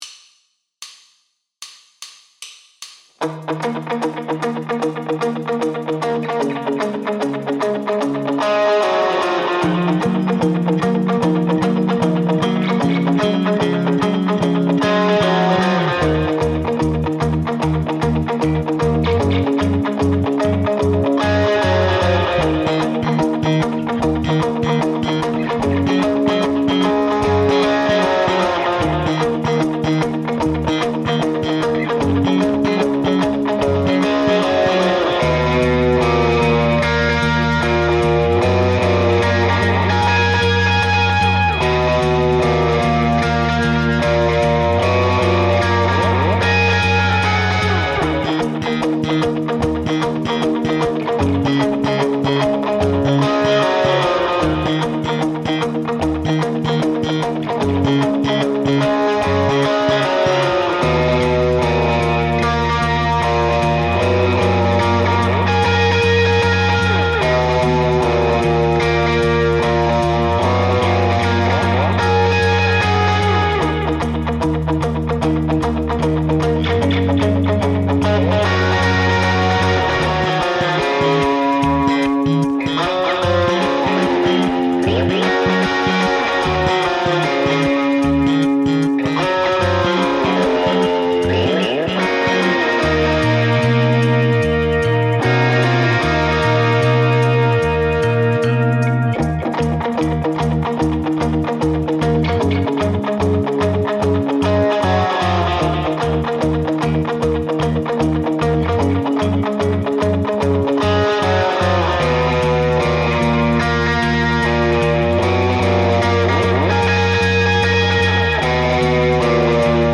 Without vocals
Based on the album and rare live version